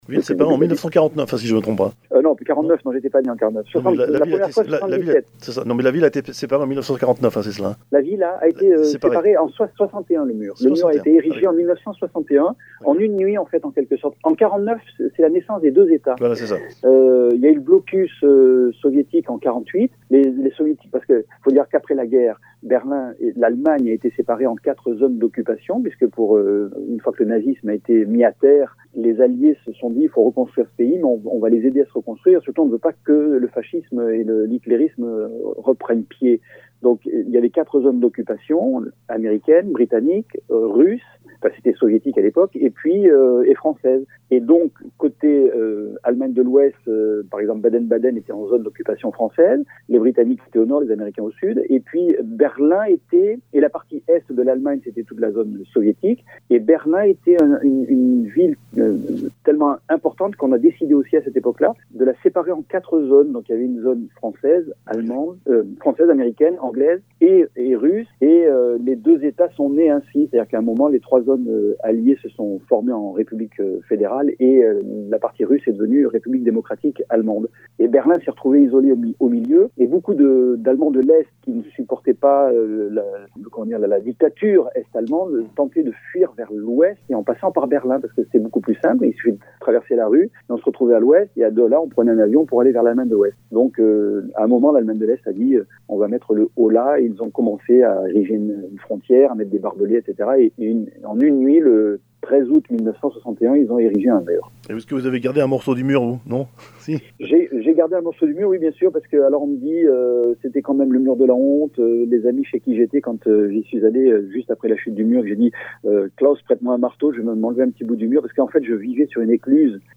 Interview de Bernard Thomasson (4 parties)